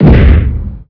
punch1.wav